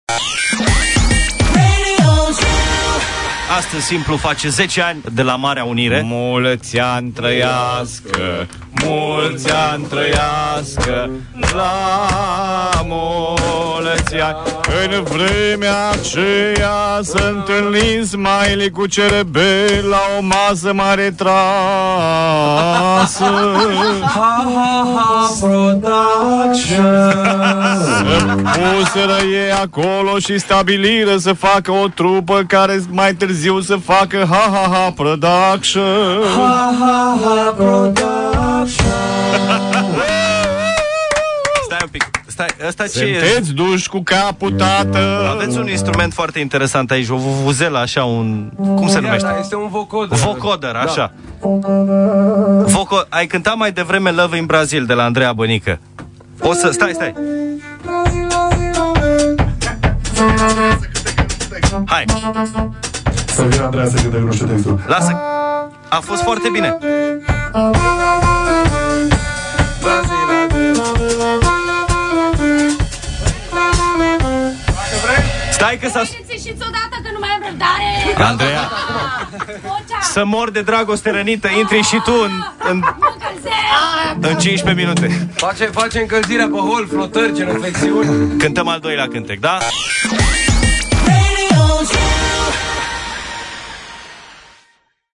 ZUper artisti live la ZU
Simplu au aniversat 10 ani in direct la Radio ZU